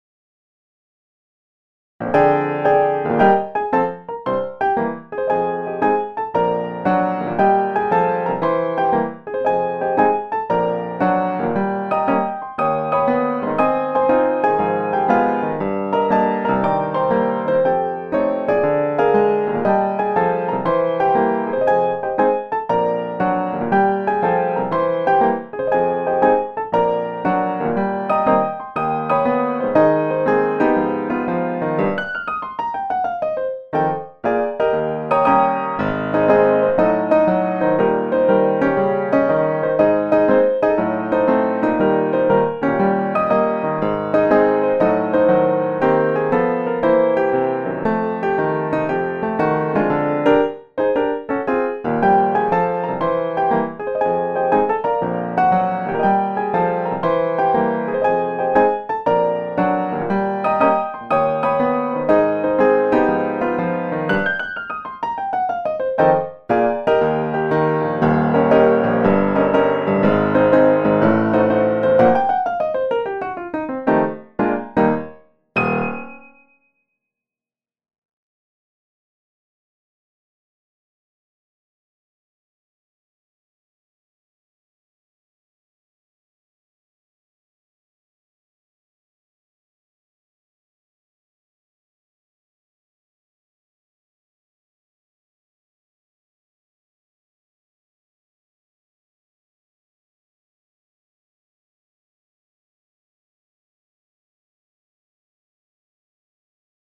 Dafür bekommt man ein Klavier, das aus verschiedenen Klavieren physikalisch modelliert wurde und das mit einer ganzen Reihe von Presets ausgestattet ist.
Neben den schon reichlich vorhandenen Klangbeispielen auf der Modartt Webseite (siehe Link weiter oben), habe ich hier noch ein paar Stücke mit verschiedenen Presets aufgenommen:
Die MIDI Dateien wurden mir von Modartt zur Verfügung gestellt.